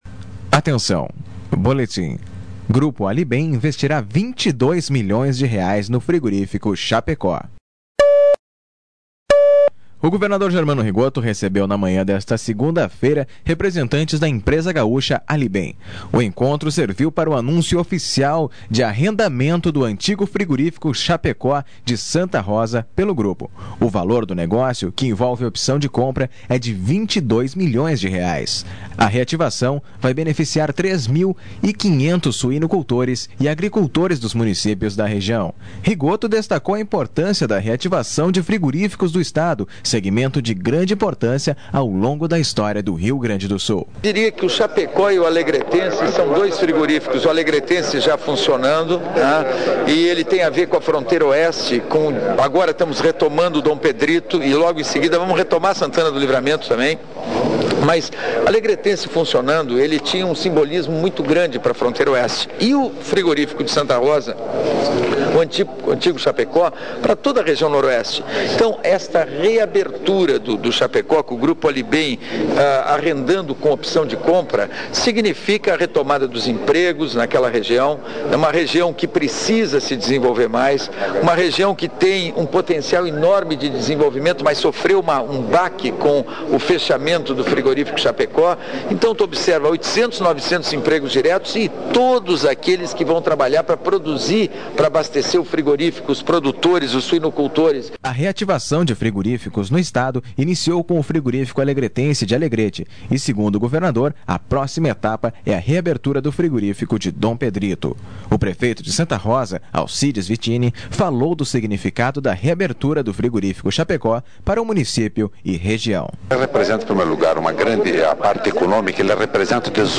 O encontro serviu para o anúncio oficial de arrendamento do antigo Frigorífico Chapecó, de Santa Rosa, pelo Grupo. (Sonoras: governador germano Rigo